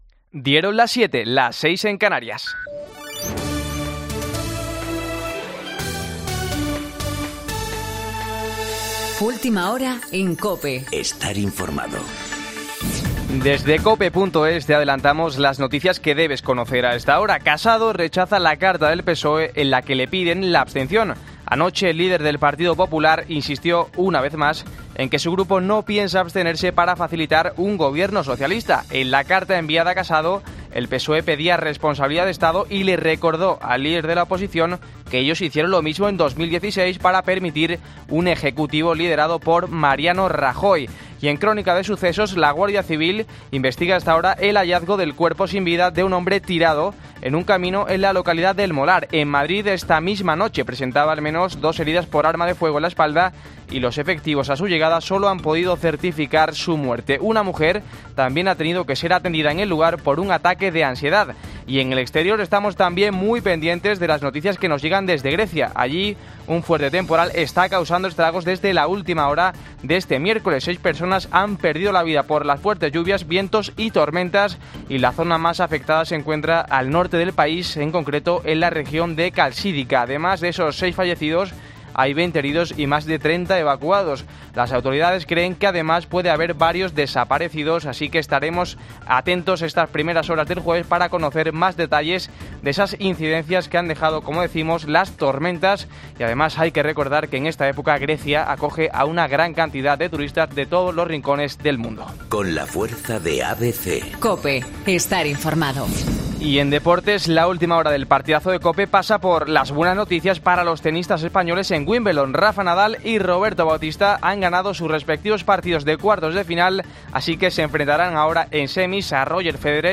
Boletín de noticias COPE del 11 de julio a las 07.00